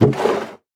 Minecraft Version Minecraft Version latest Latest Release | Latest Snapshot latest / assets / minecraft / sounds / block / barrel / open1.ogg Compare With Compare With Latest Release | Latest Snapshot